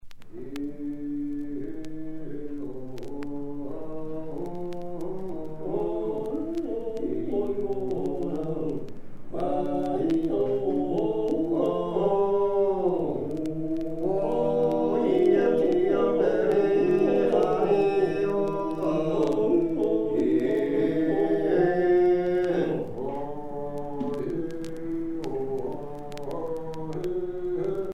Chants de travail
Pièce musicale éditée